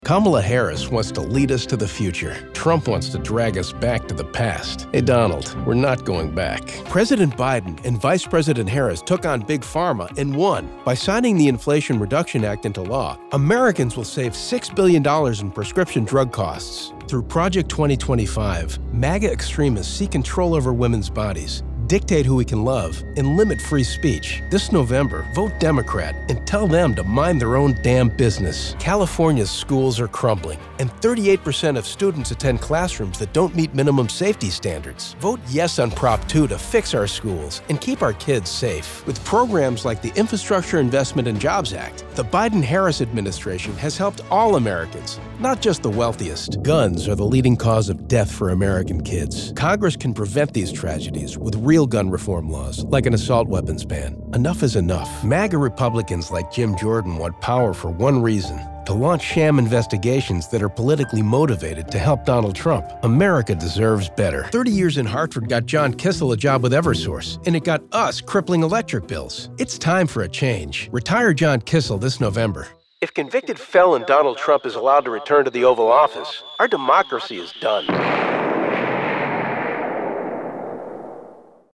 Male
Adult (30-50)
My voice is in the lower mid range and has a natural warmth. I project a natural and sincere tone that works with various attitudes from friendliness to confident coolness.
Democratic Spots
Democrat Campaign Ads